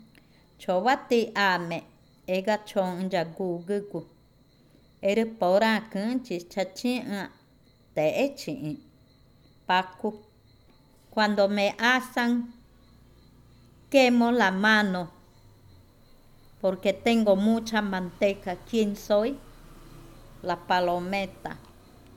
Cushillococha